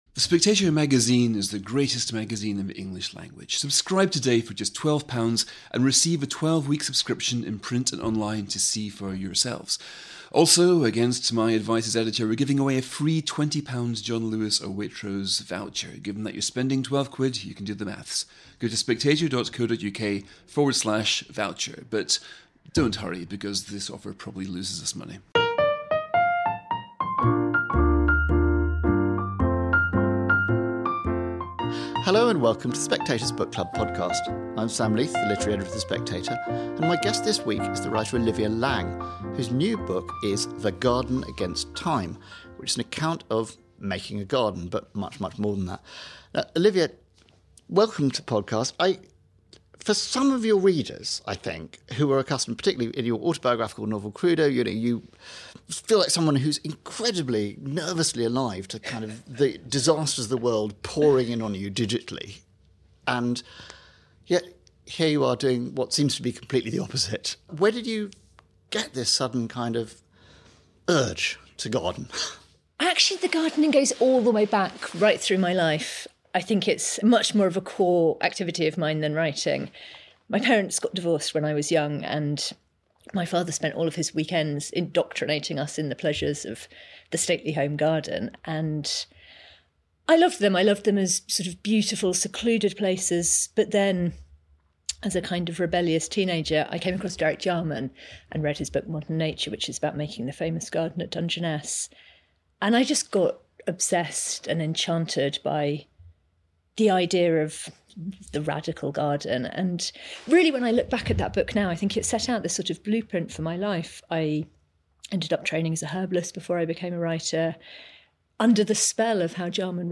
On this week's Book Club podcast I'm joined by Olivia Laing to talk about her new book The Garden Against Time: In Search of a Common Paradise.